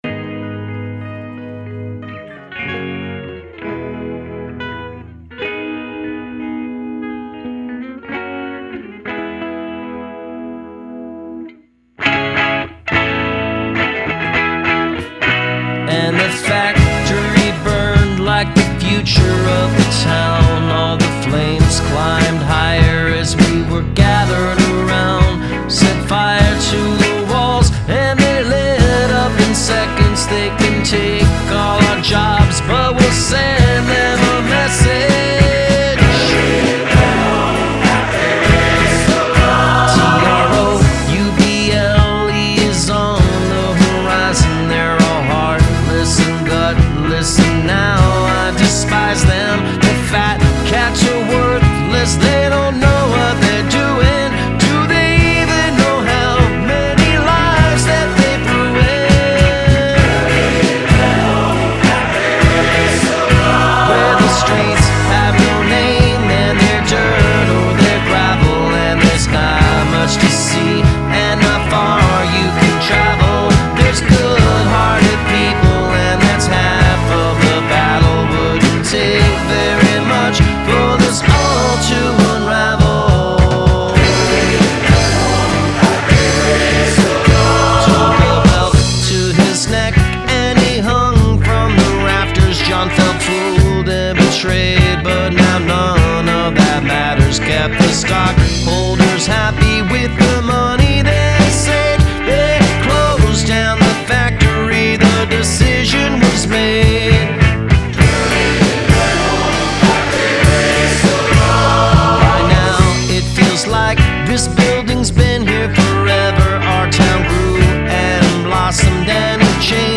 The drums , bass and guitar are all nice.